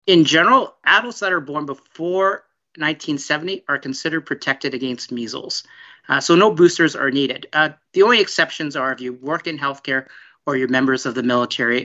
During a media call on Tuesday, they said the region is an outbreak situation which requires some extra precaution for the youngest population in the area.